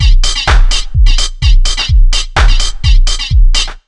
127BEAT4 3-L.wav